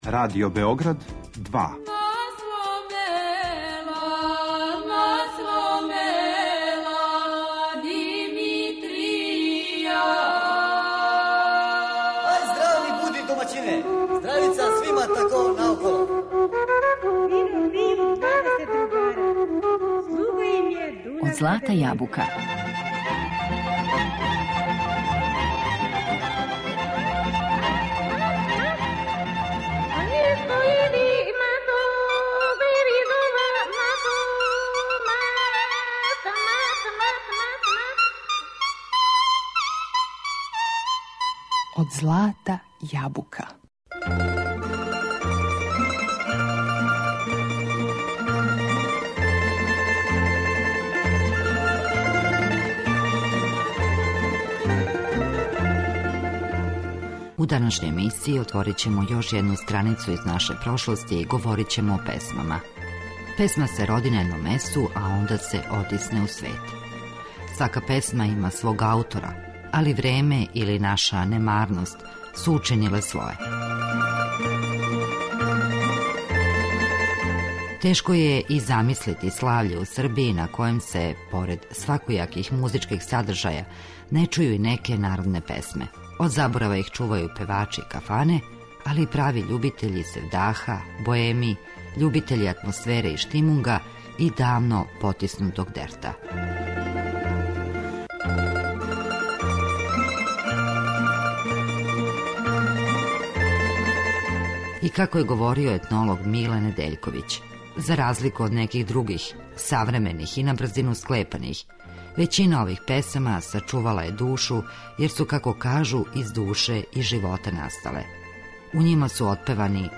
Данас отварамо нашу песмарицу и чућемо још неке старе, добре песме, као и причу о томе када и где су настале и како су забележене.